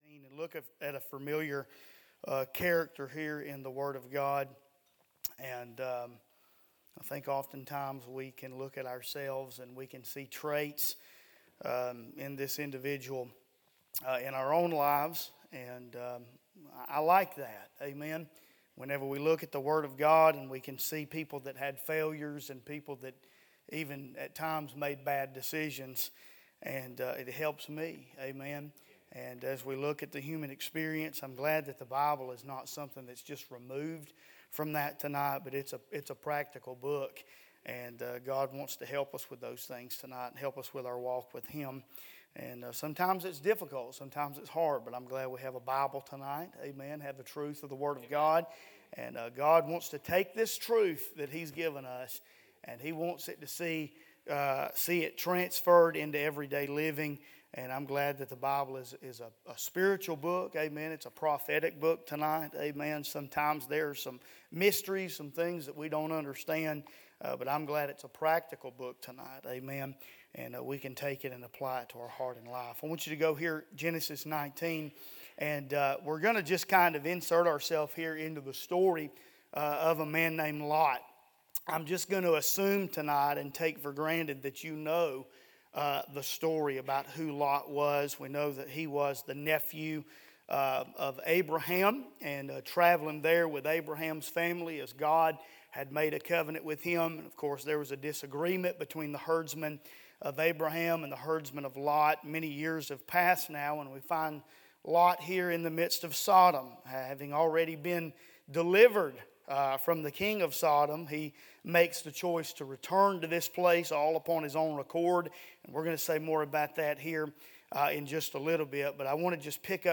Listen to the most recent sermons from Bible Baptist Church